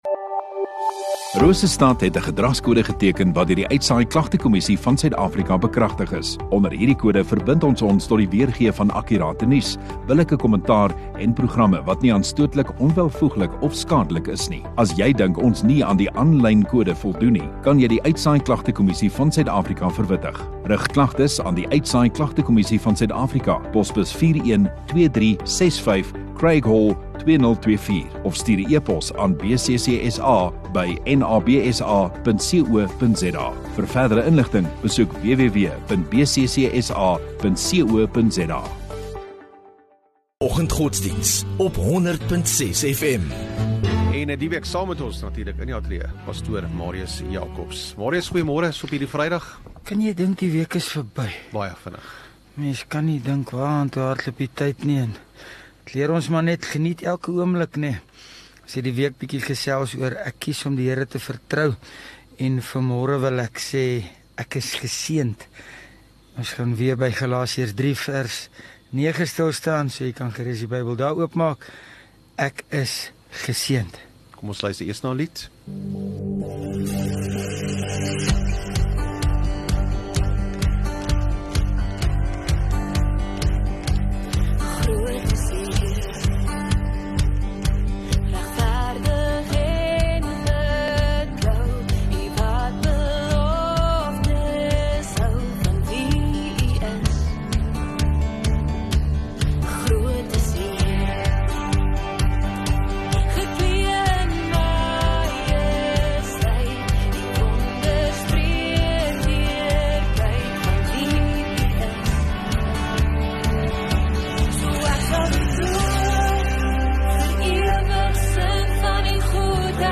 View Promo Continue Install Rosestad Godsdiens 1 Nov Vrydag Oggenddiens